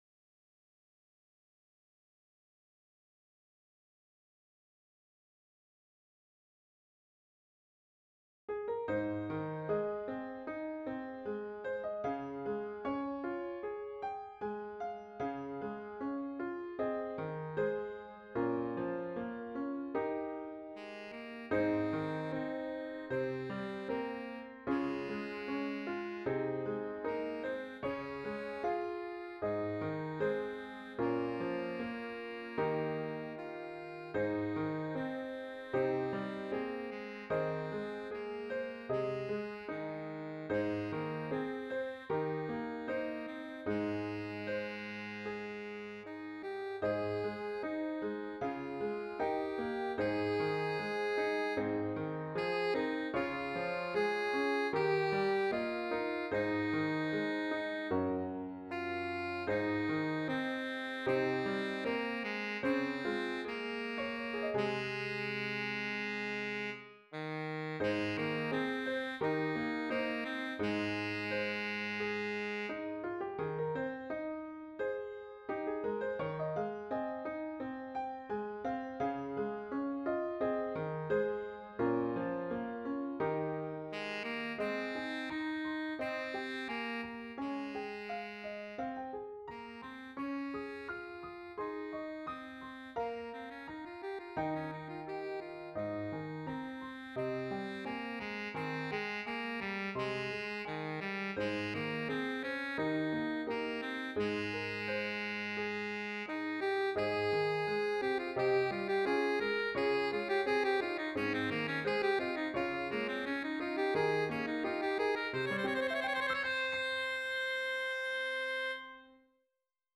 Intermediate Instrumental Solo with Piano Accompaniment.
Christian, Gospel, Sacred, Folk.
A Hymn arrangement
put to a flowing folk setting.